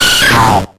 Cries
HITMONTOP.ogg